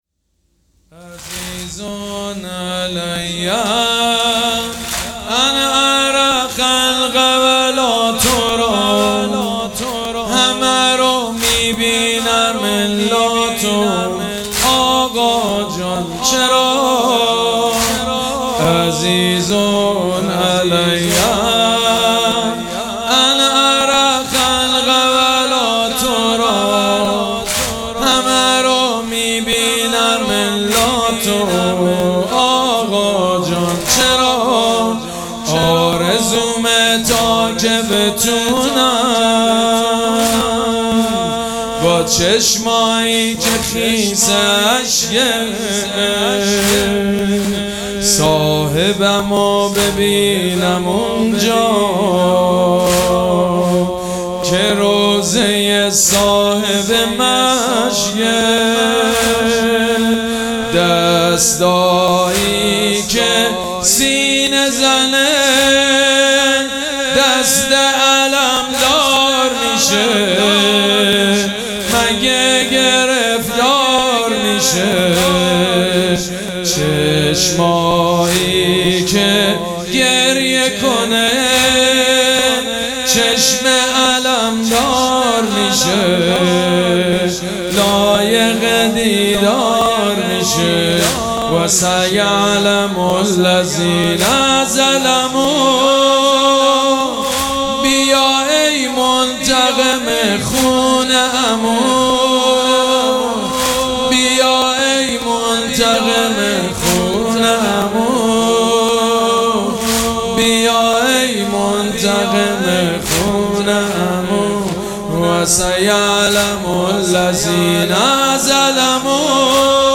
مراسم عزاداری شب نهم محرم الحرام ۱۴۴۷
حاج سید مجید بنی فاطمه